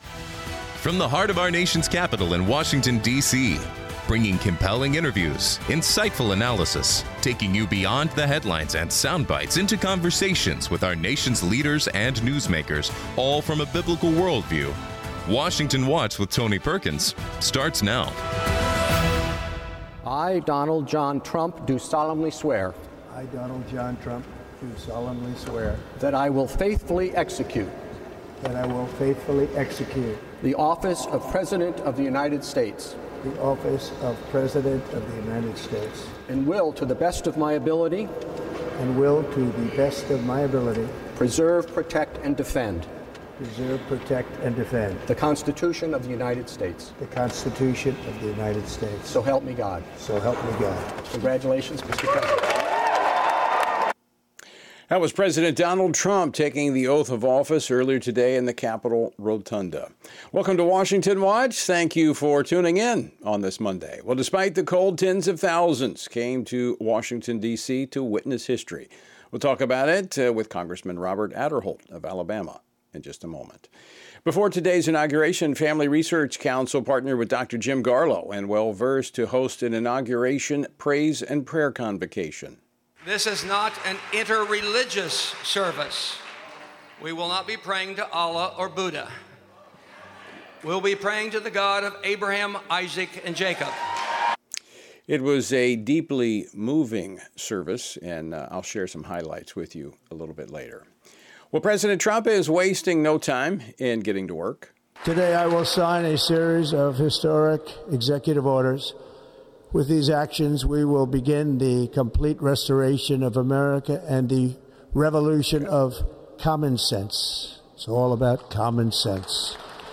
On today's program: Robert Aderholt, U.S. Representative for the 4th District of Alabama, shares his reflections on the second inauguration of President Donald Trump. Tony Perkins, President of Family Research Council, shares a message at FRC and Well Versed’s Inauguration Praise & Prayer Convocation.